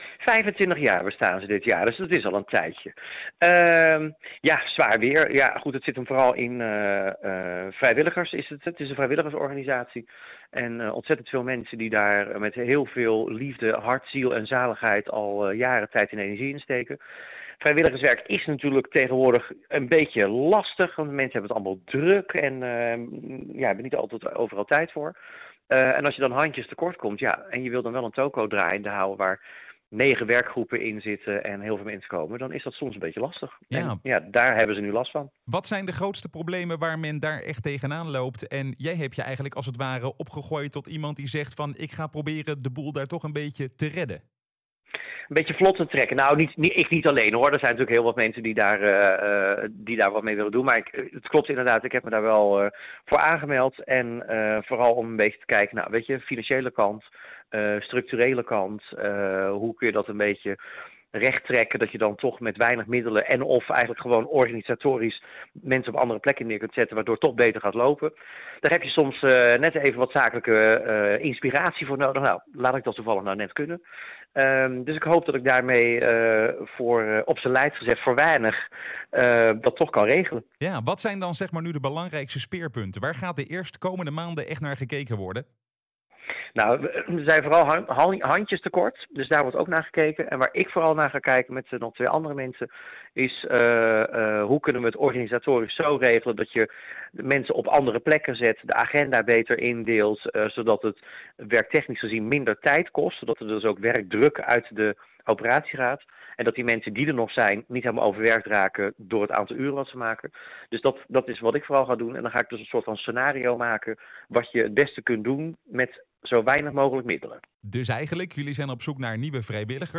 Interview Leiden Maatschappij Nieuws